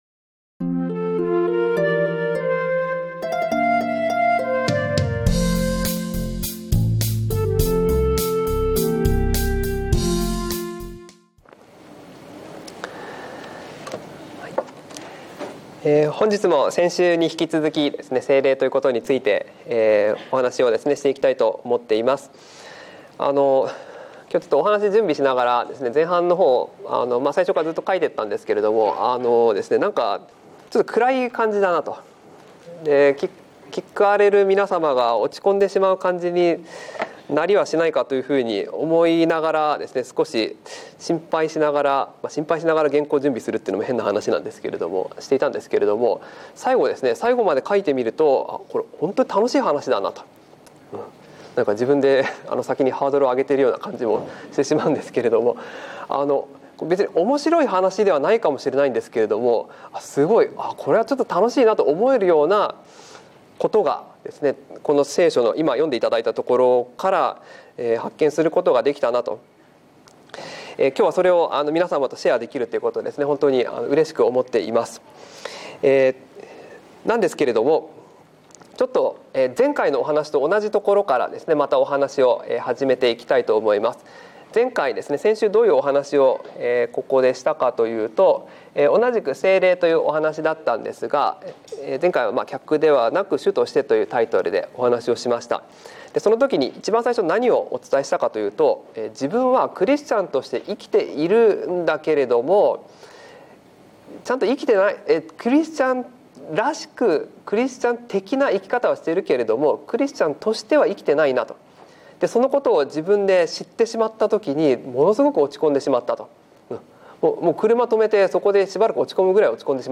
礼拝メッセージ175 聖霊：信仰「と」生活ではなくて
収録：セブンスデー・アドベンチスト三育学院キリスト教会